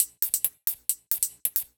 Index of /musicradar/ultimate-hihat-samples/135bpm
UHH_ElectroHatD_135-04.wav